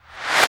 69 RV CLAP-L.wav